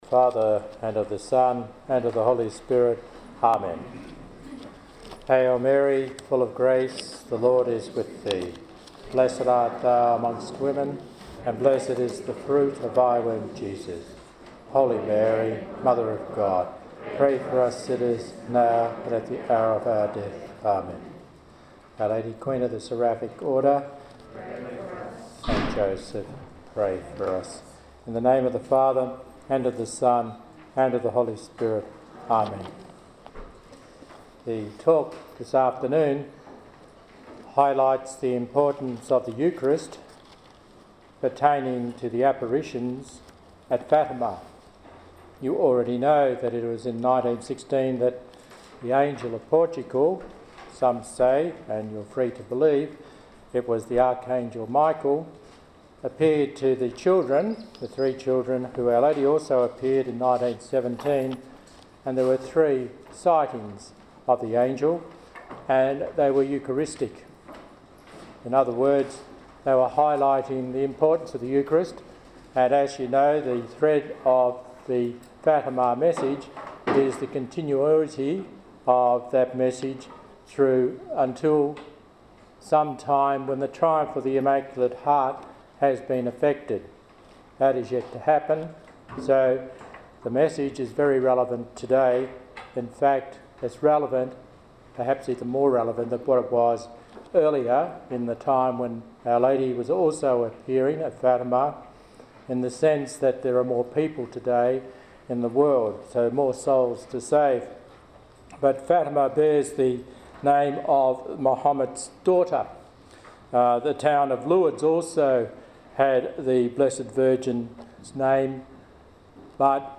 during the "Day With Mary" held at Good Shepherd Catholic Church in Kelmscott, Western Australia on 3 March 2012.